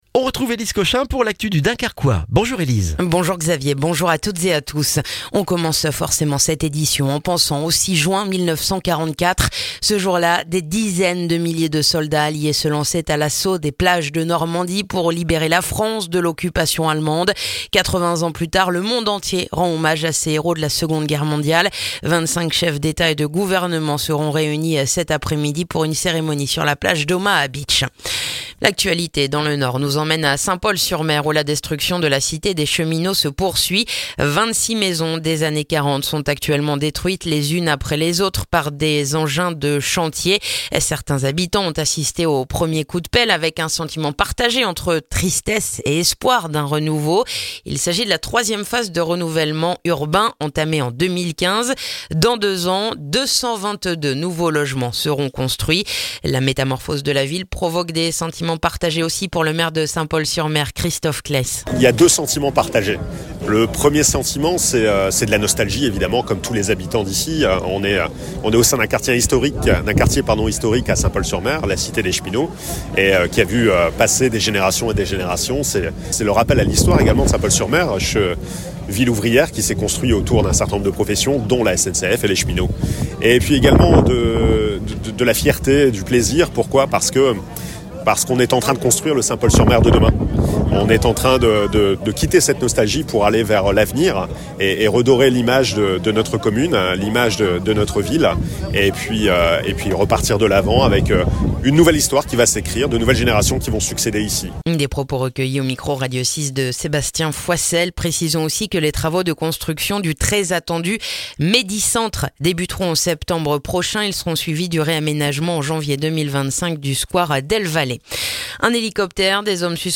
Le journal du jeudi 6 juin dans le dunkerquois